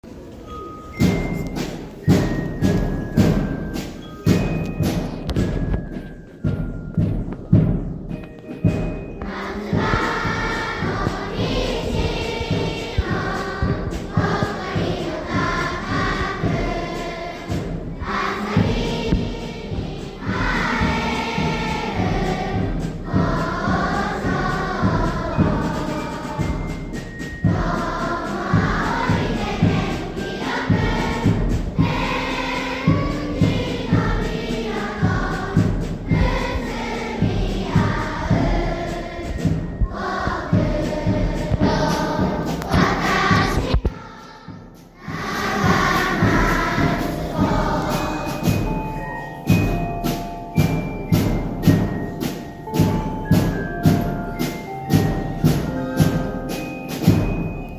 全校朝会で歌った校歌を録音しました。